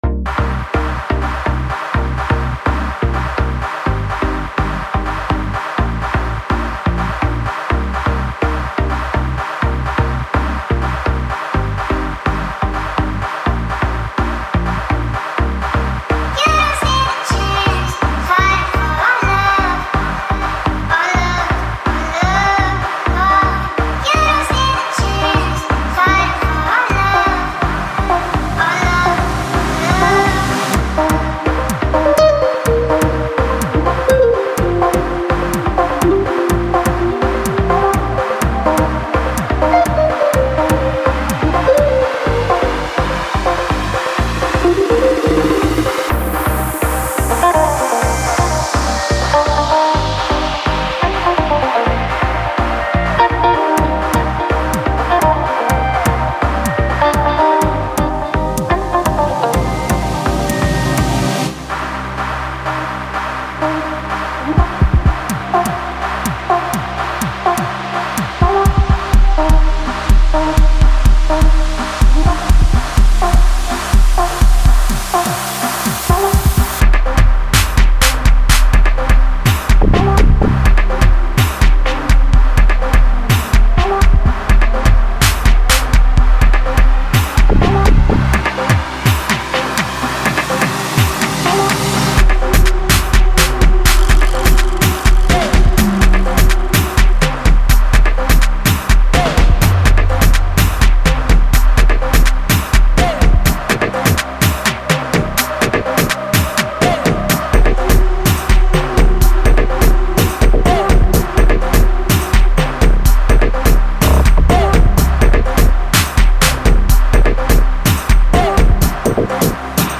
catchy melodies